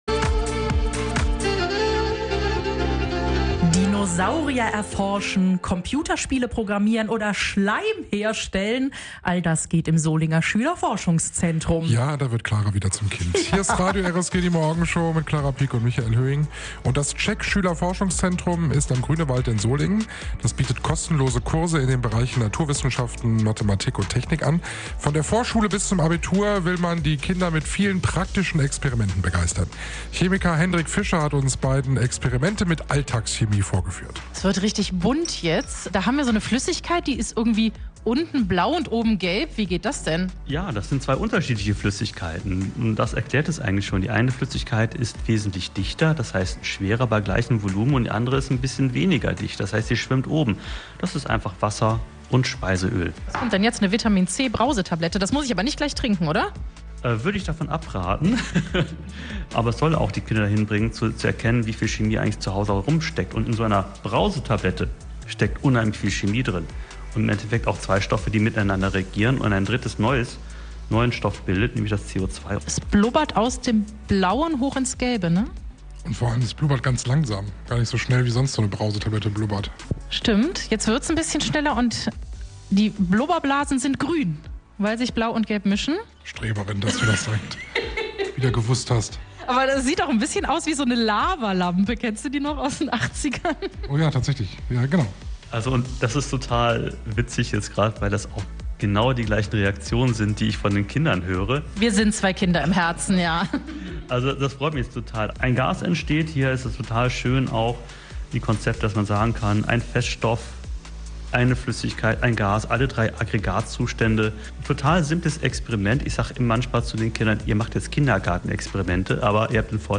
Die beiden waren im Solinger Schülerforschungszentrum.